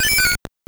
Cri d'Axoloto dans Pokémon Or et Argent.